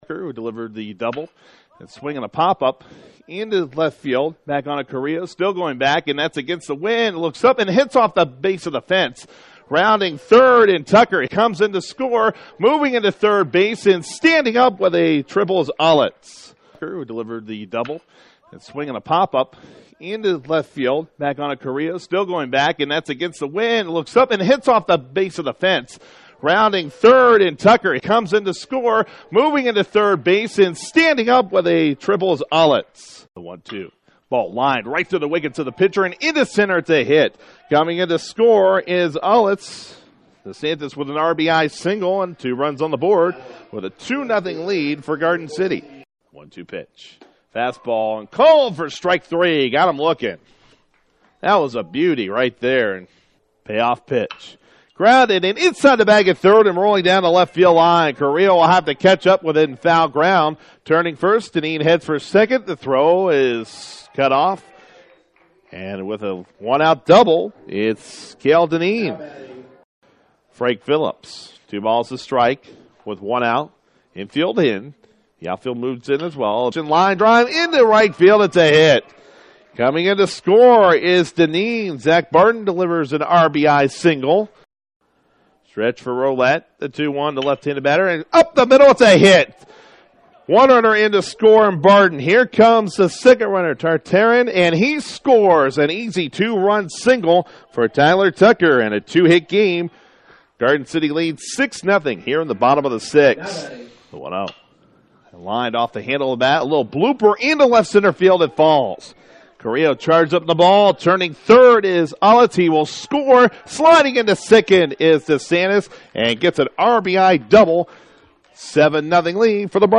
Highlights
bronc-buster-baseball-highights.mp3